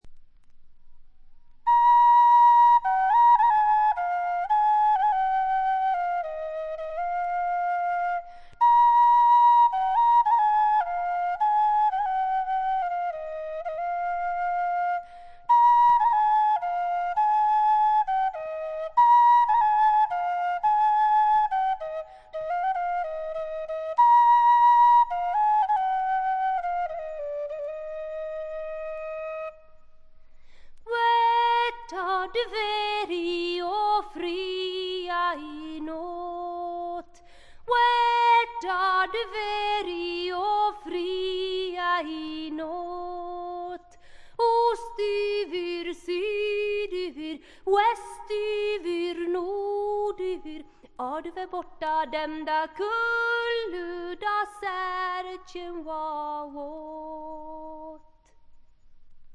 軽微なプツ音が2回ぐらい出たかな？という程度でほとんどノイズ感無し。
ちょっとポップなフォーク・ロックも素晴らしいし激渋のトラッドも良いです。
試聴曲は現品からの取り込み音源です。